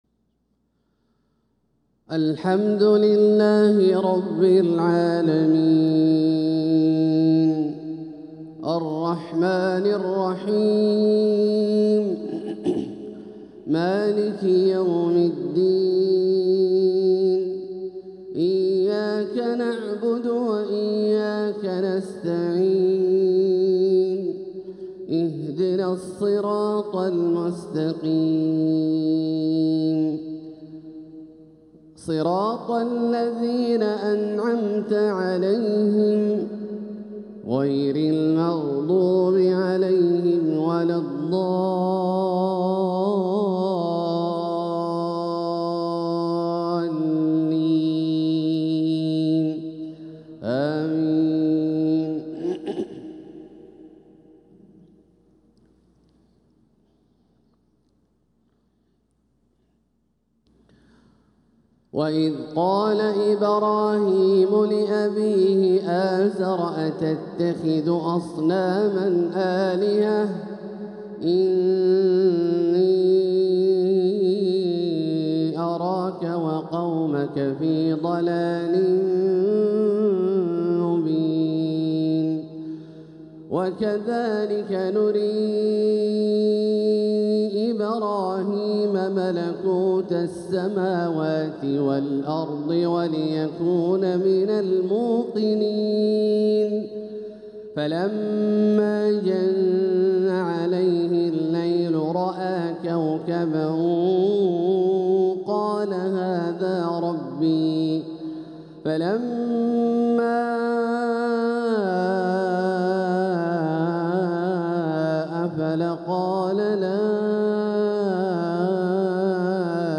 تلاوة تحبيرية من سورة الأنعام | فجر الأربعاء 8-3-1446هـ > ١٤٤٦ هـ > الفروض - تلاوات عبدالله الجهني